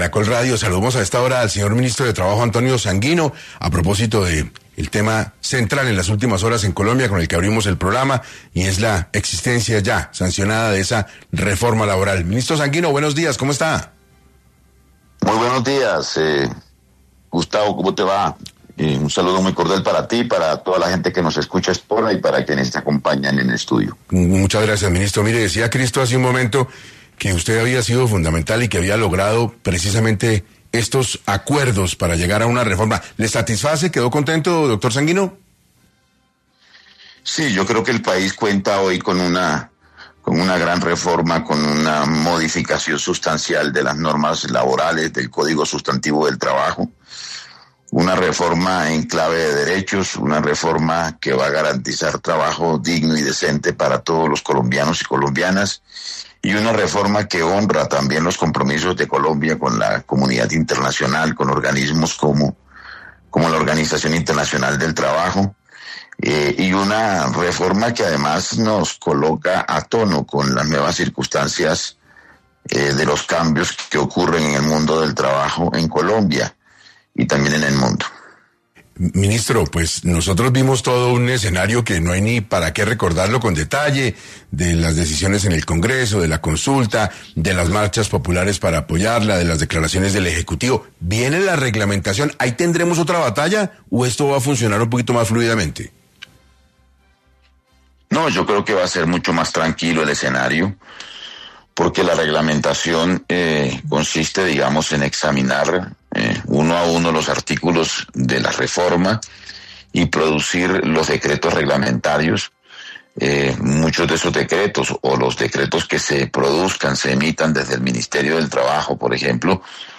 Uno de sus principales promotores y conciliadores fue el actual ministro de Trabajo, Antonio Sanguino, quien en entrevista para 6AM manifestó su satisfacción con el acuerdo alcanzado y explicó la forma en la que la Ley será aplicada.